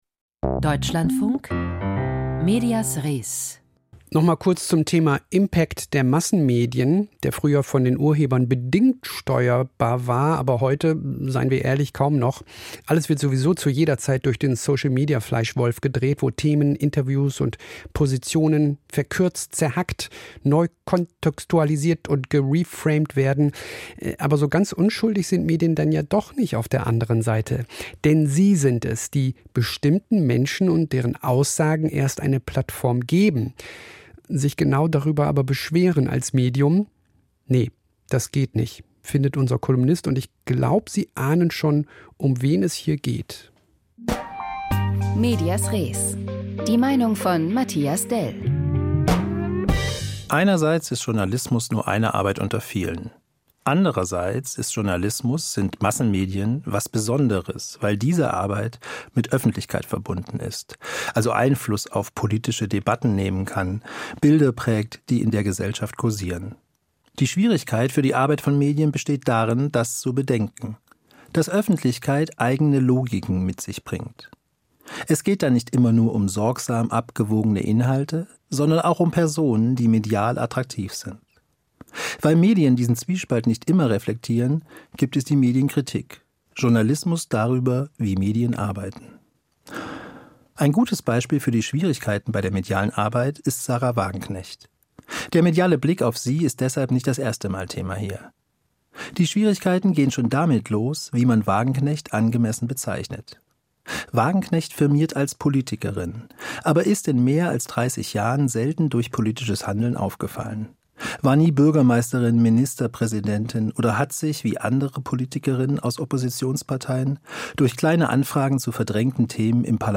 Kolumne: Polit-Influencerin Sahra Wagenknecht